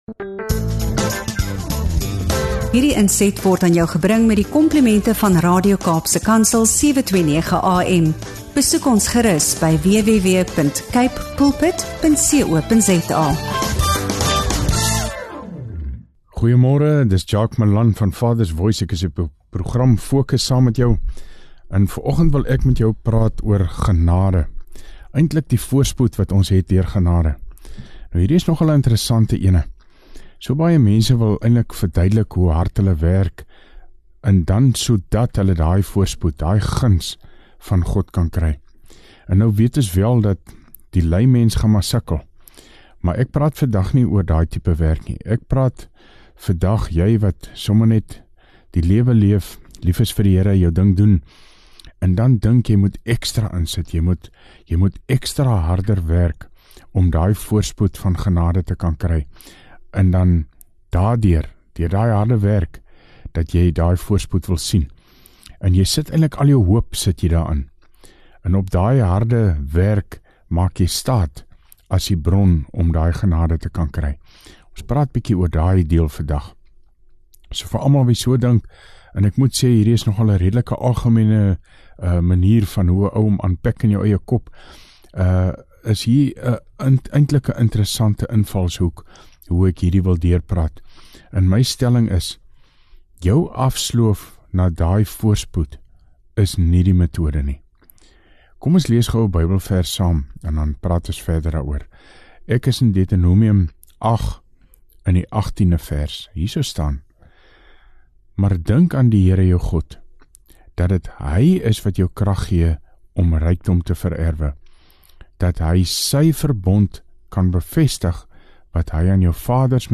FOCUS & FOKUS DEVOTIONALS